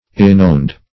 Irrenowned \Ir`re*nowned"\